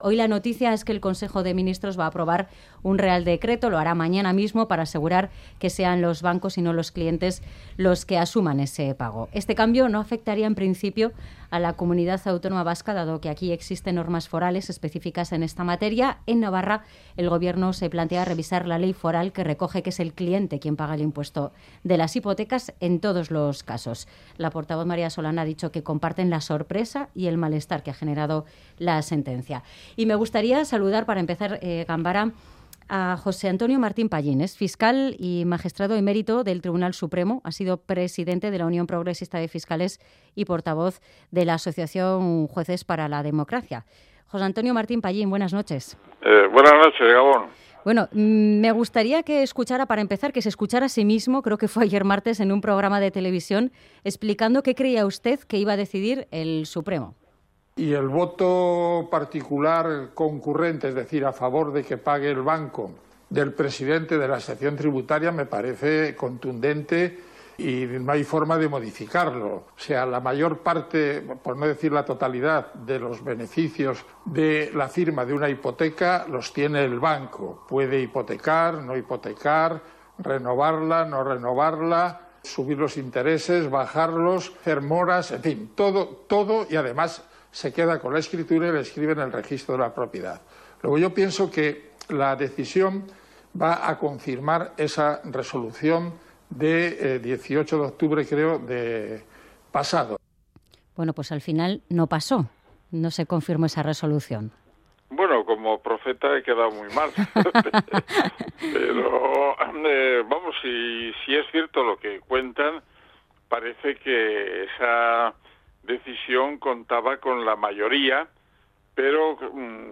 Audio: Entrevista a José Antonio Martín Pallín juez emérito del Tribunal Supremo